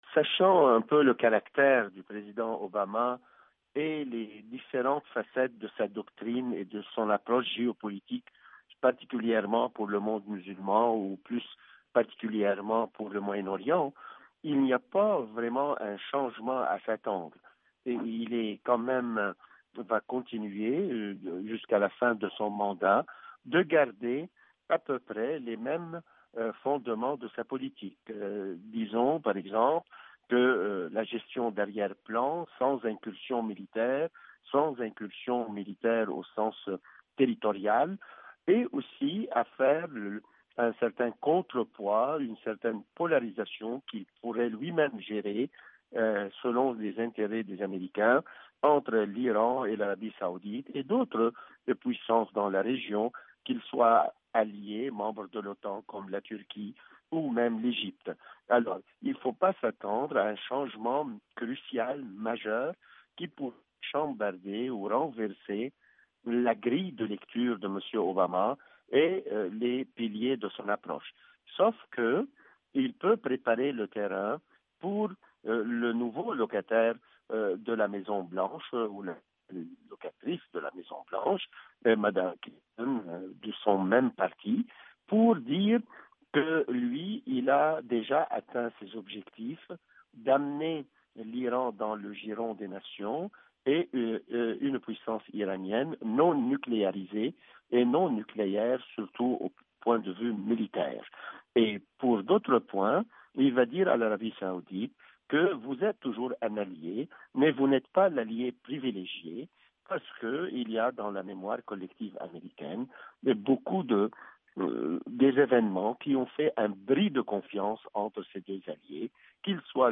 a joint